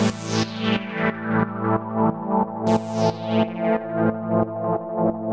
GnS_Pad-alesis1:8_90-A.wav